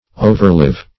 Overlive \O`ver*live"\, v. t.